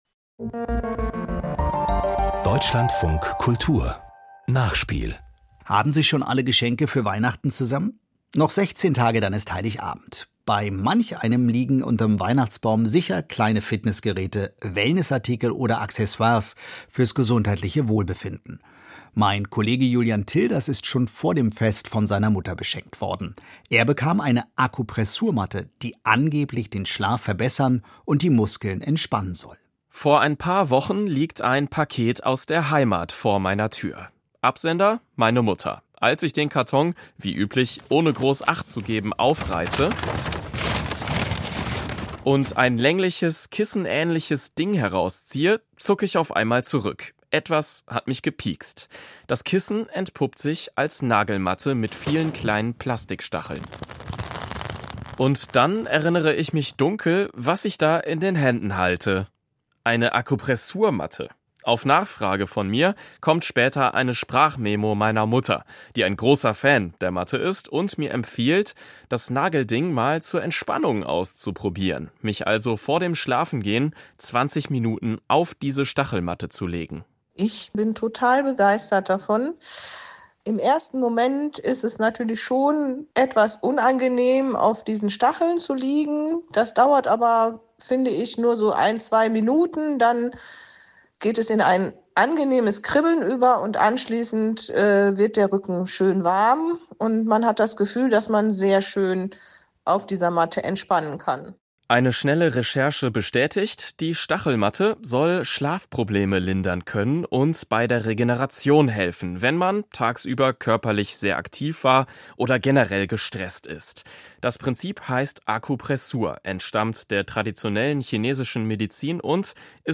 Ein Erfahrungsbericht mit Einschätzungen aus der Schlafforschung und Sportmedizin.